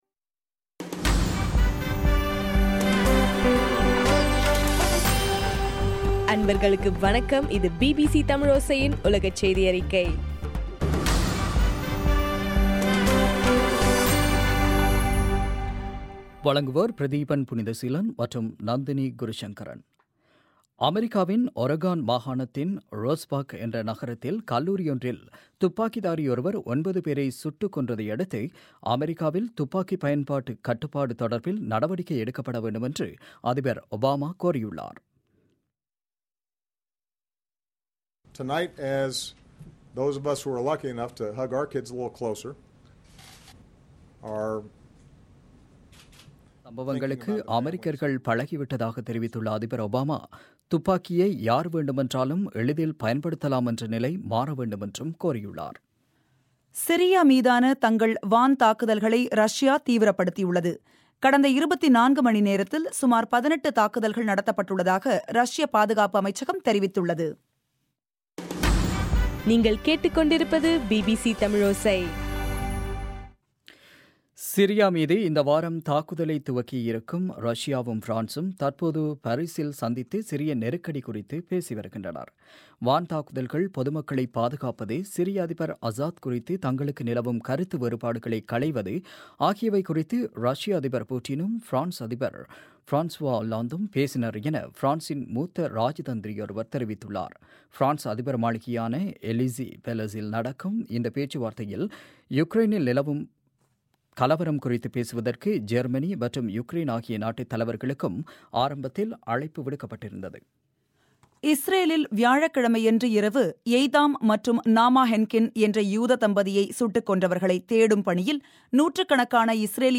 அக்டோபர்-2, 2015 பிபிசி தமிழோசையின் உலகச் செய்திகள்